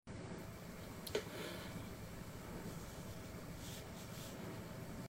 Satisfying AI ASMR Pimple Popper!